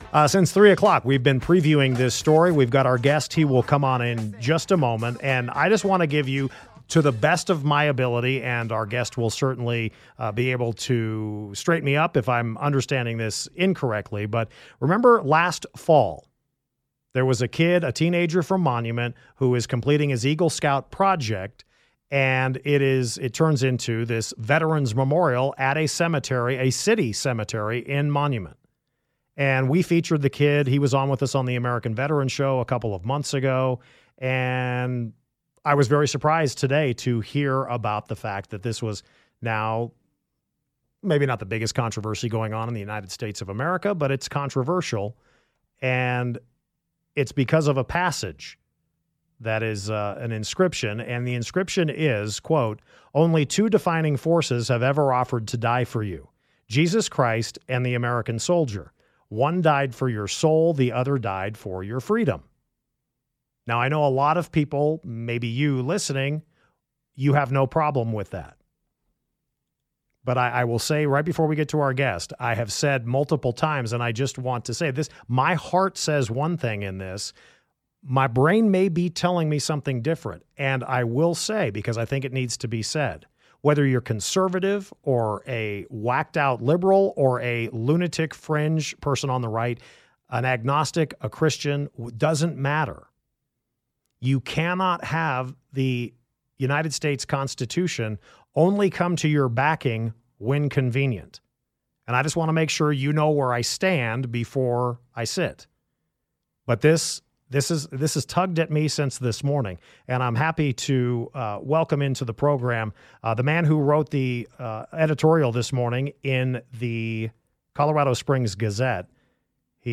Talk Radio 710 KNUS